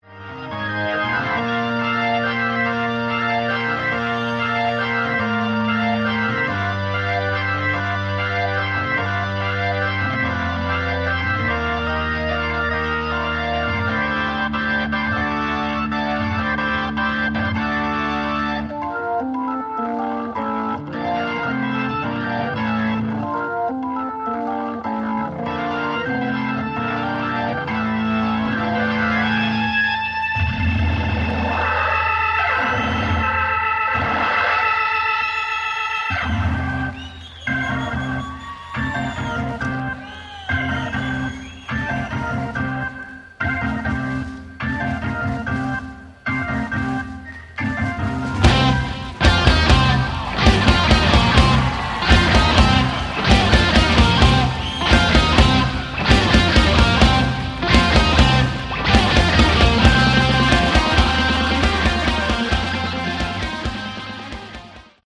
Category: Classic Hard Rock
vocals
guitar
keyboards
bass
drums